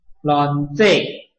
臺灣客語拼音學習網-客語聽讀拼-海陸腔-單韻母